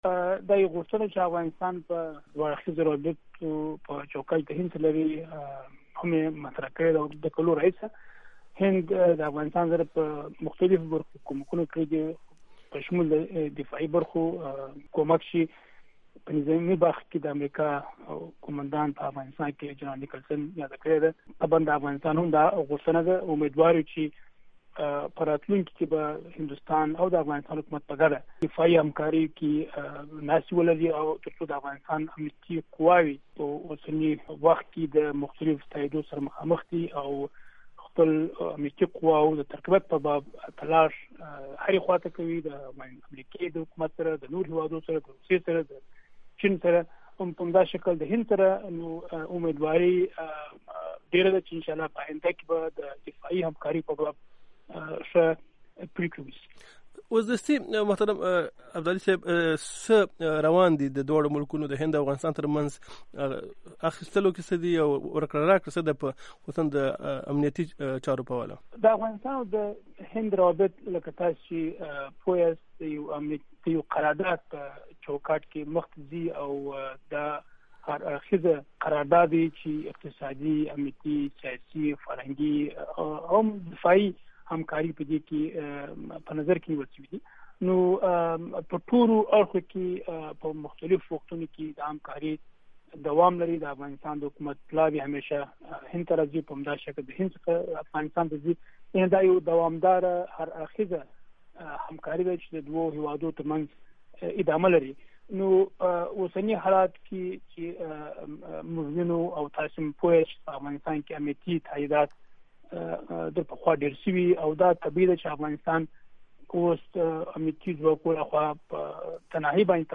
مرکې
هند کې د افغانستان ډاکټر شېدا محمد ابدالي سره د امریکا غږ اشنا راډیو مرکه